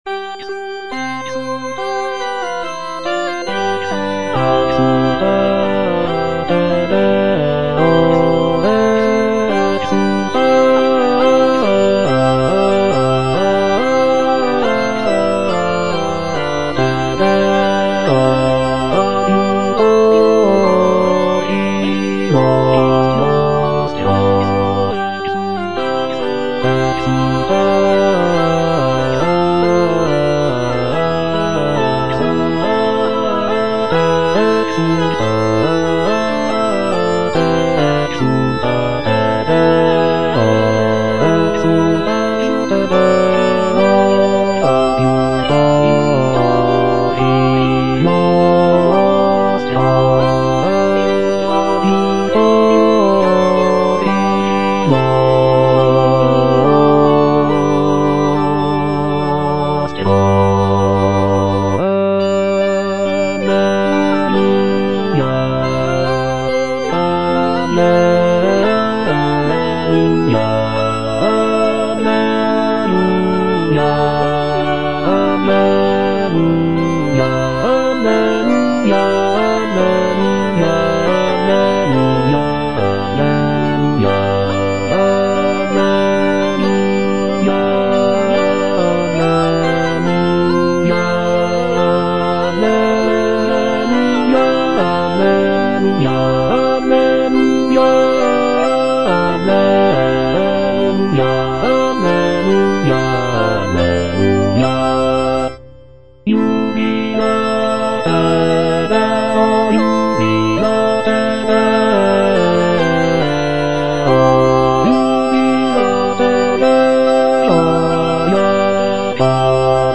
A.SCARLATTI - EXULTATE DEO (EDITION 2) Bass (Emphasised voice and other voices) Ads stop: auto-stop
"Exultate Deo (edition 2)" by A. Scarlatti is a sacred choral work that showcases the composer's mastery of the Baroque style. The piece is known for its uplifting and jubilant tone, with intricate counterpoint and rich harmonies.